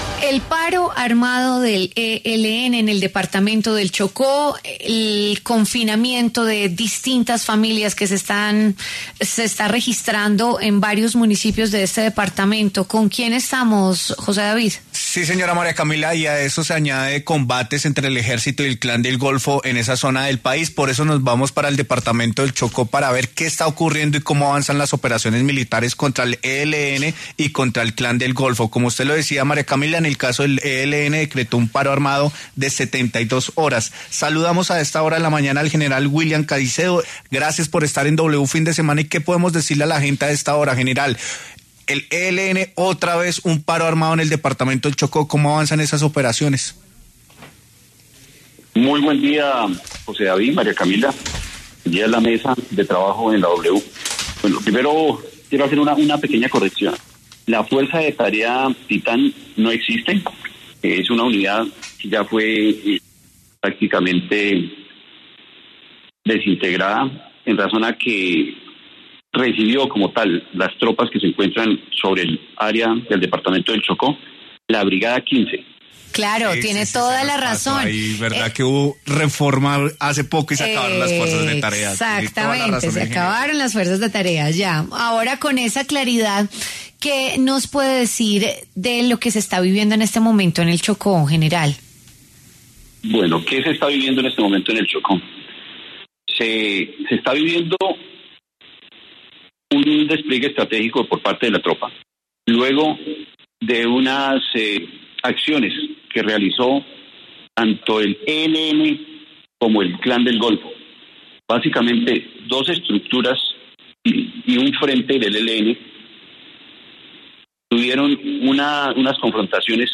El general William Caicedo, habló en W Fin de Semana sobre el paro armado que se extenderá en el departamento de Chocó durante 72 horas.